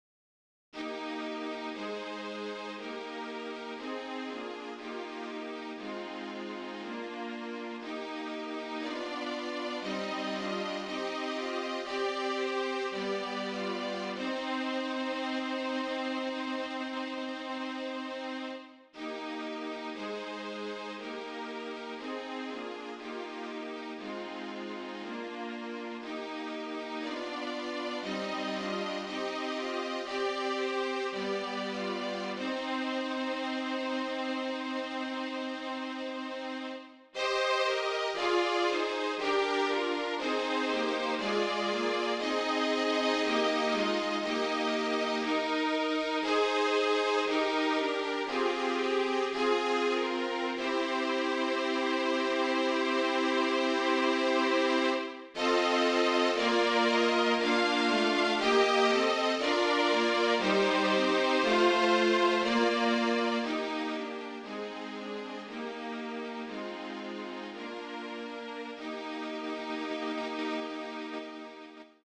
MIXED DUOS
MIDI